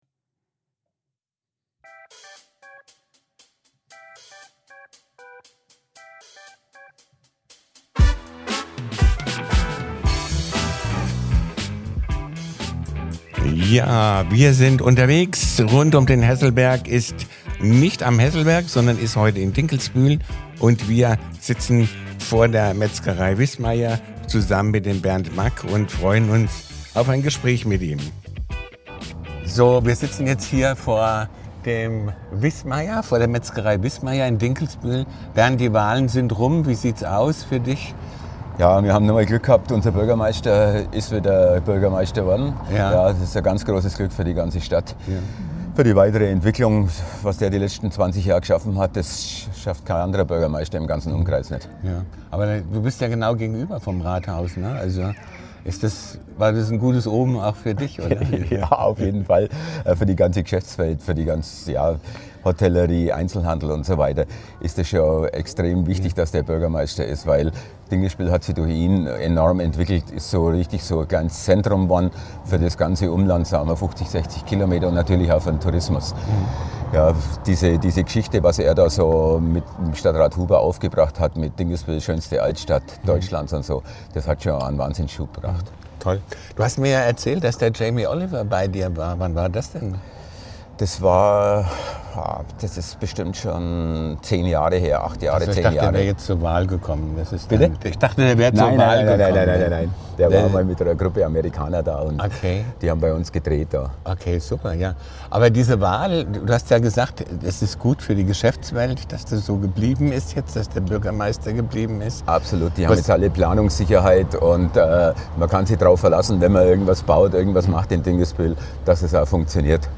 Ein Gespräch über Metzgerleidenschaft, Stadtentwicklung und kreative Wurstideen aus Dinkelsbühl.